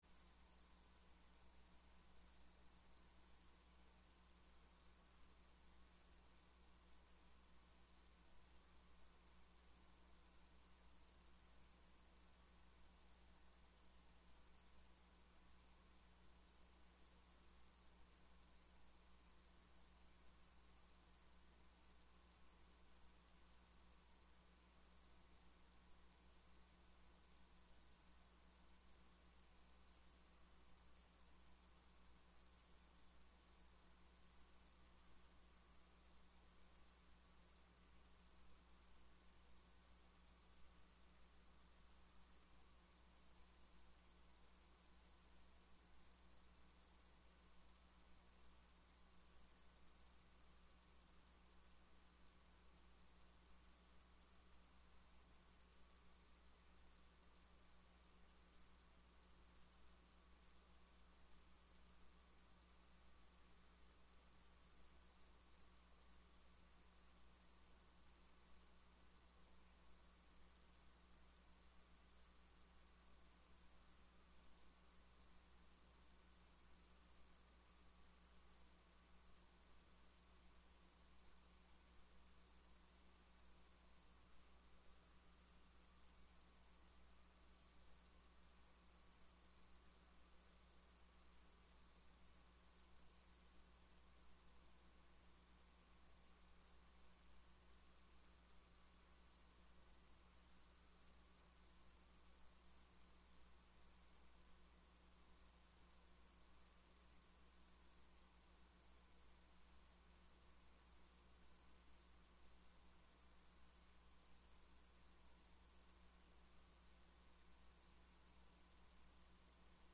Oradores das Explicações Pessoais (33ª Ordinária da 3ª Sessão Legislativa da 7ª Legislatura)